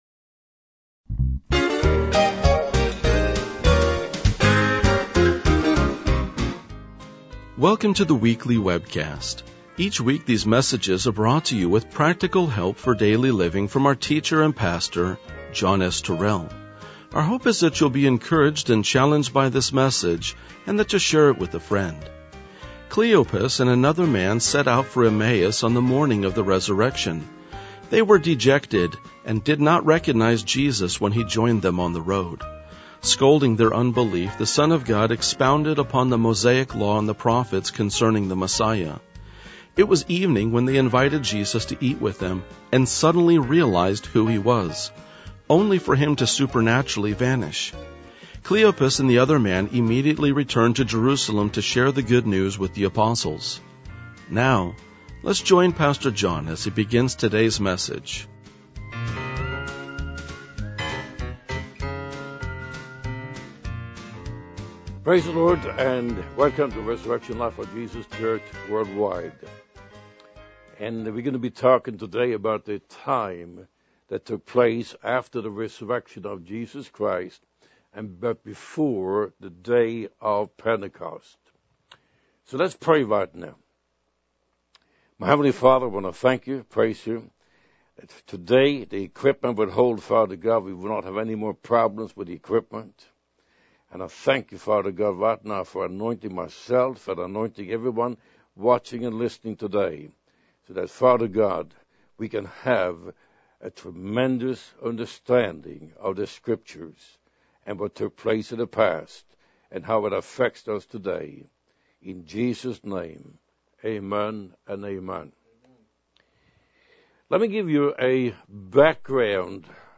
RLJ-1993-Sermon.mp3